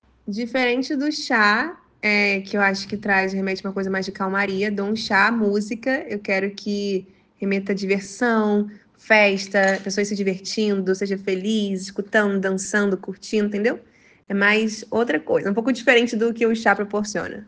Resposta em áudio: